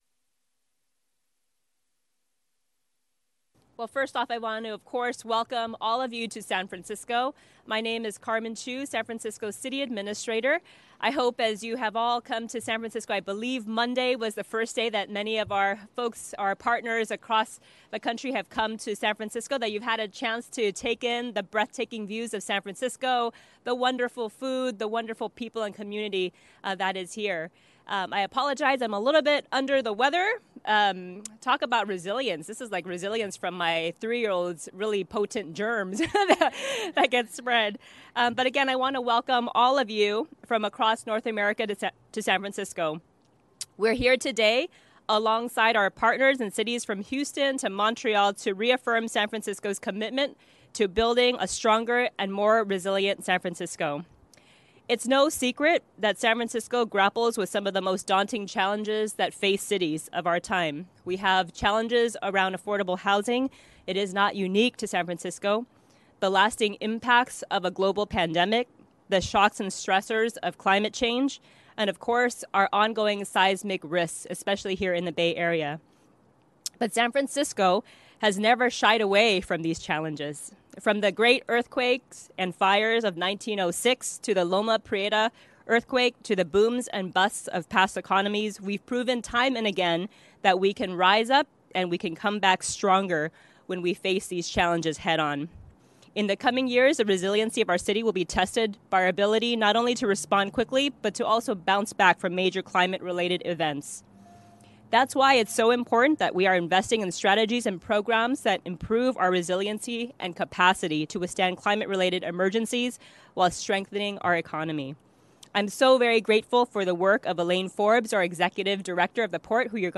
Press Conference Audio